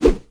HandSwing3.wav